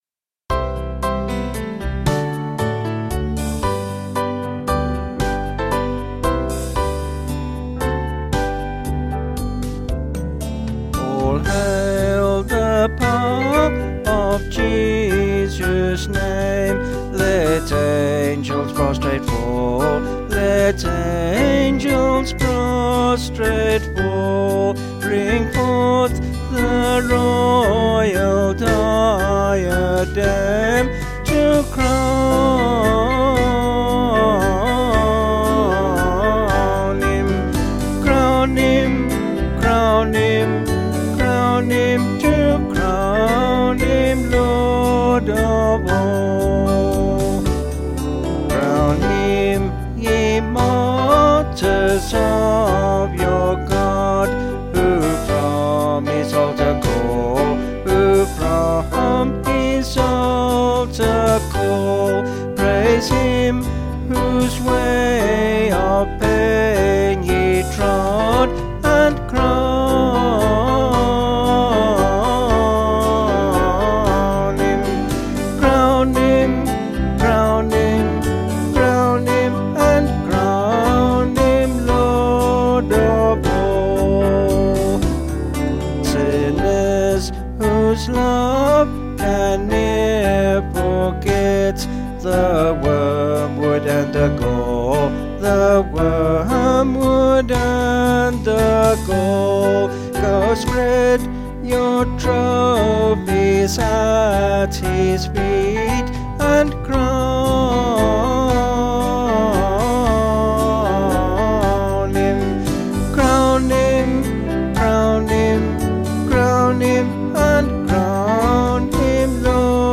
Vocals and Band   264.8kb Sung Lyrics